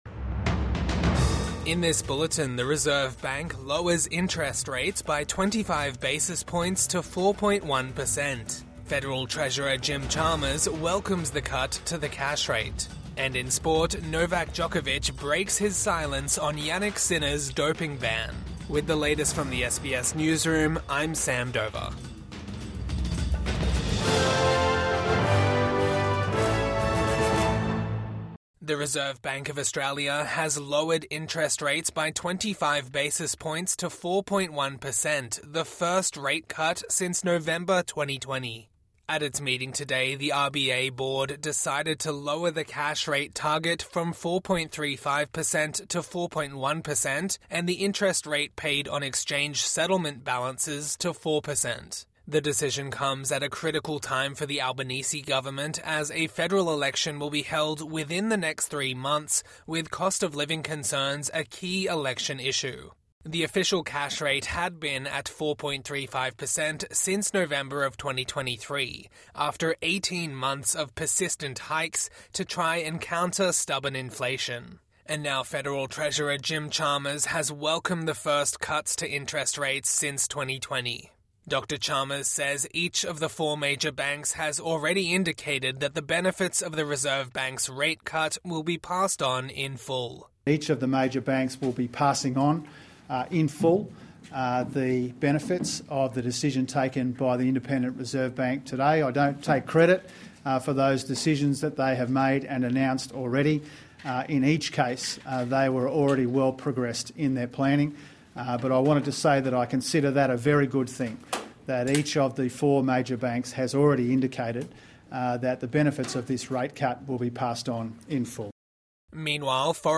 Evening News Bulletin 18 February 2025